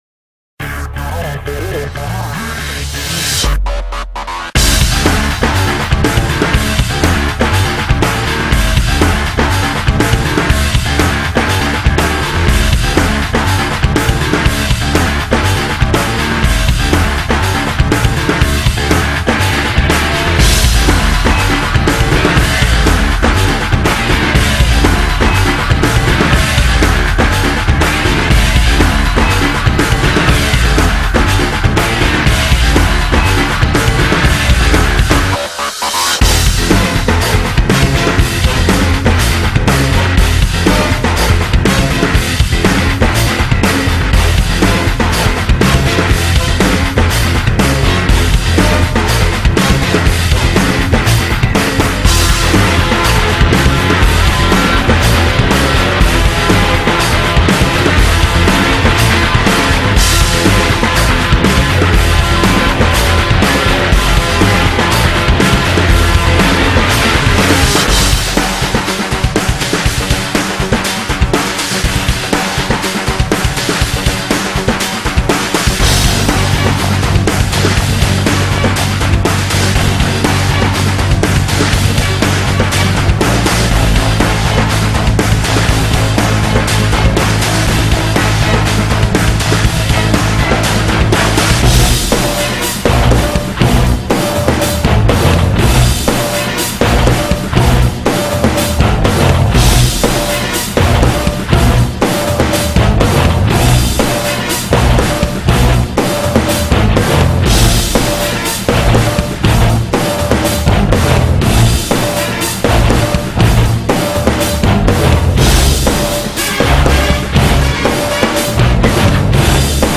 BPM121
Audio QualityMusic Cut